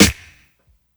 Boom-Bap Snare 58.wav